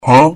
Huh Sound Effect Free Download
Huh